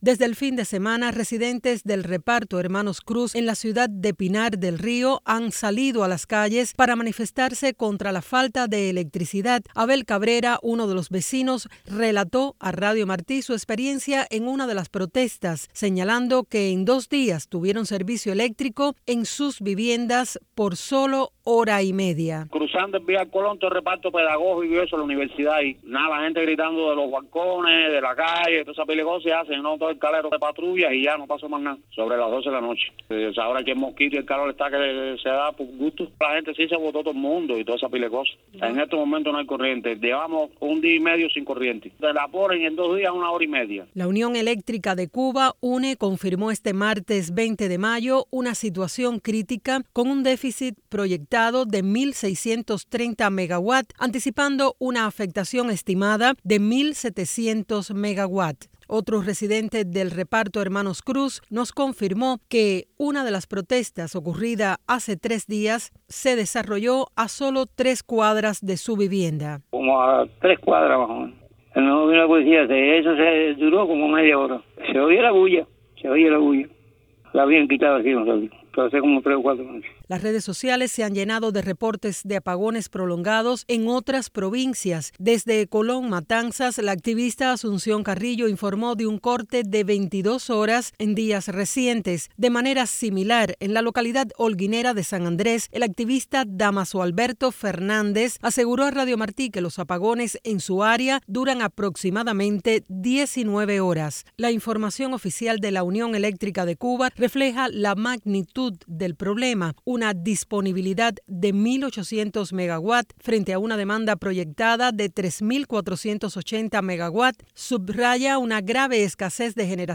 Testimonios de las protestas en el barrio El Calero, en Pinar del Río